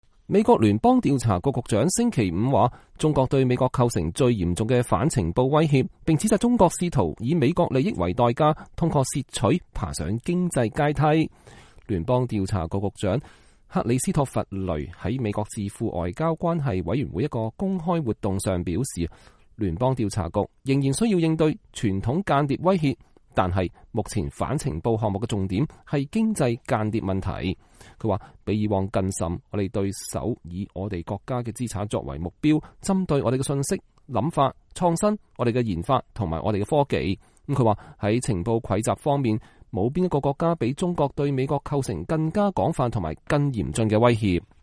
聯邦調查局局長克里斯托弗雷2019年4月26日在智庫外交關係委員會發表講話。
聯調局局長克里斯托弗·雷（Christopher Wray）在美國智庫外交關係委員會（Council on Foreign Relations）的一場公開活動上表示，聯調局仍然需要應對傳統間諜威脅，但是目前反情報項目的重點是經濟間諜問題。